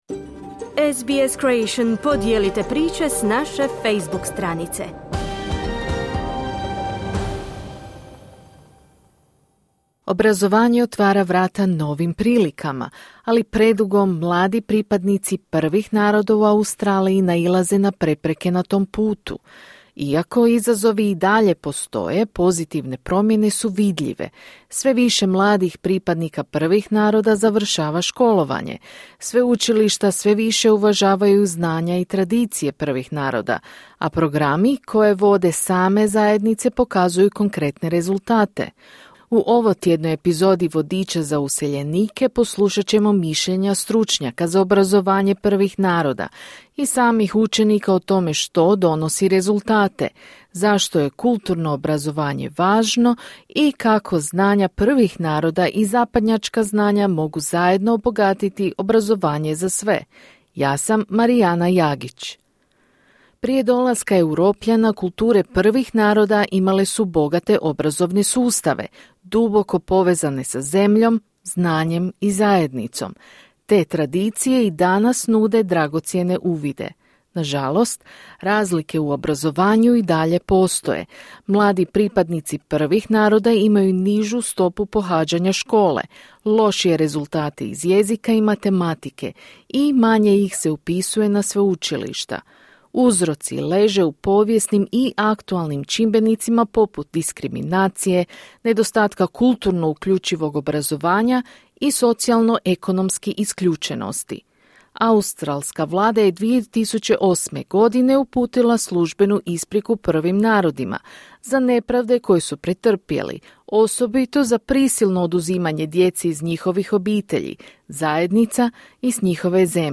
Sve više mladih pripadnika Prvih naroda završava školovanje, sveučilišta sve više uvažavaju znanja i tradicije Prvih naroda, a programi koje vode same zajednice pokazuju konkretne rezultate. U ovotjednoj epizodi Vodiča za useljenike, poslušat ćemo mišljenja stručnjaka za obrazovanje Prvih naroda i samih učenika o tome što donosi rezultate, zašto je kulturno obrazovanje važno, i kako znanja Prvih naroda i zapadnjačka znanja mogu zajedno obogatiti obrazovanje za sve.